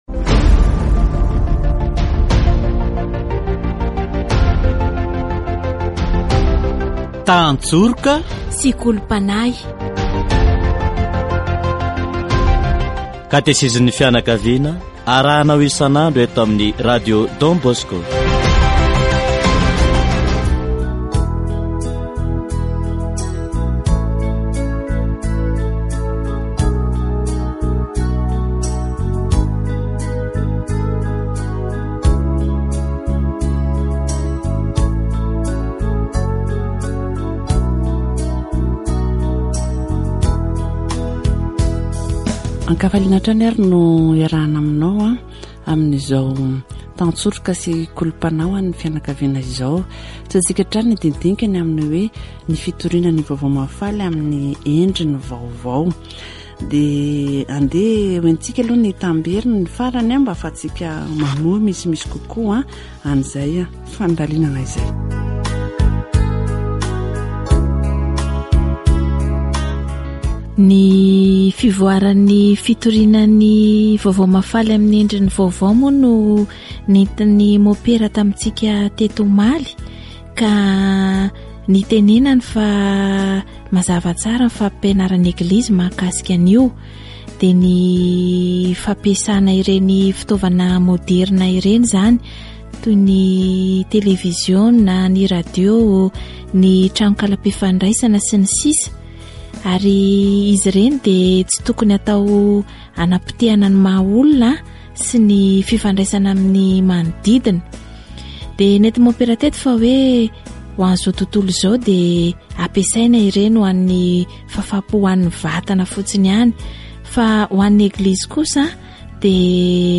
Category: Deepening faith
On November 30, 2019, the Pope met with the CPPNI, about being a Church on the way out, he stressed the no to defeatism in the new evangelization. Catechesis on the new Evangelization